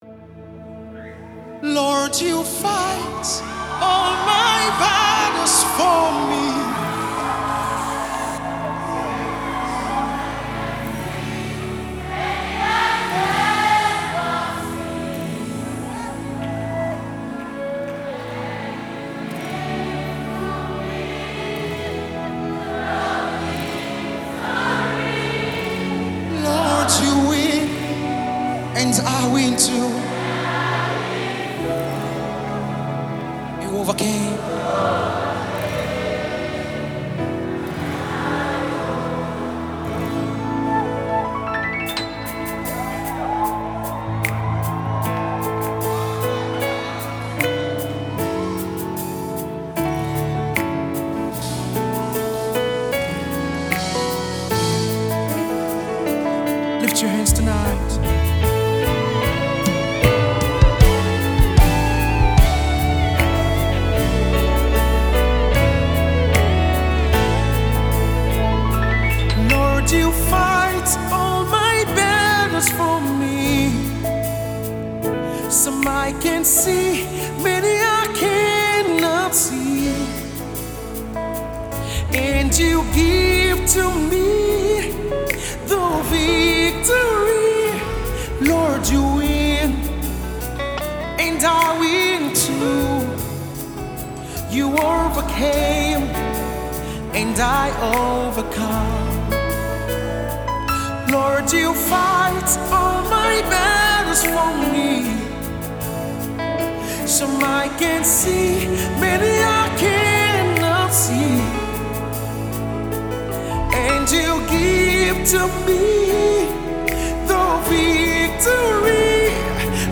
deep and rich song